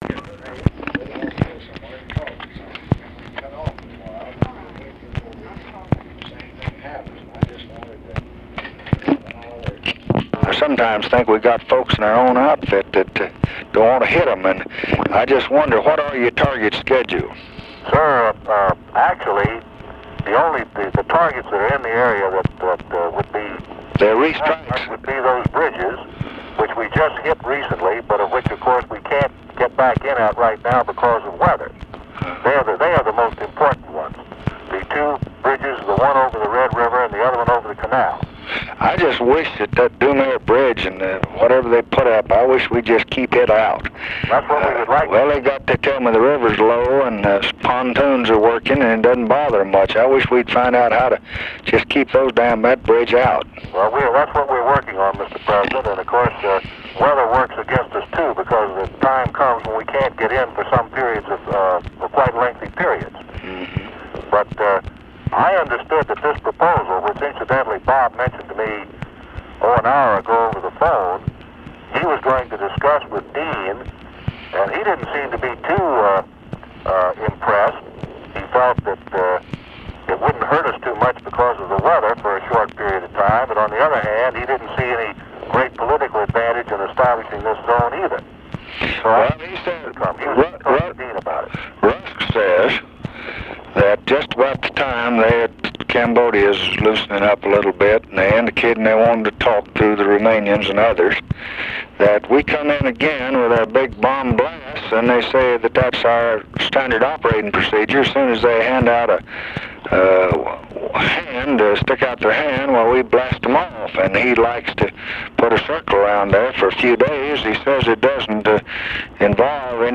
Conversation with EARLE WHEELER and OFFICE CONVERSATION, January 3, 1968
Secret White House Tapes